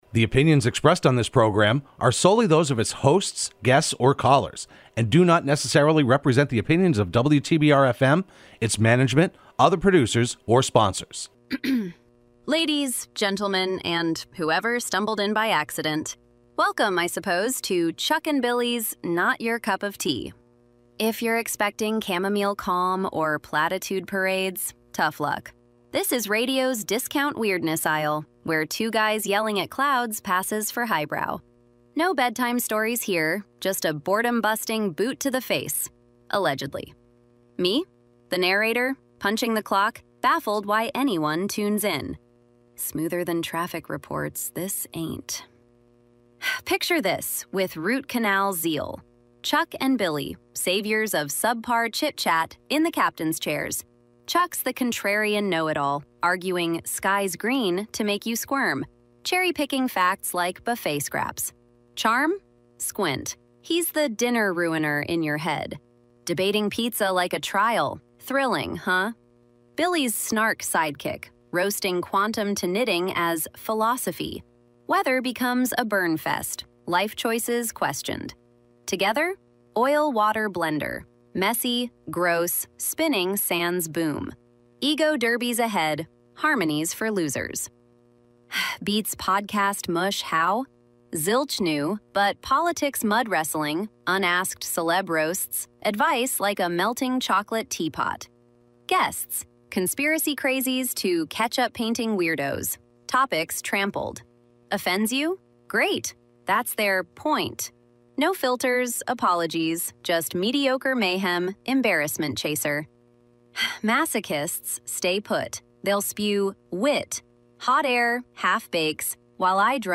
Broadcast live every Wednesday afternoon at 3:30pm on WTBR.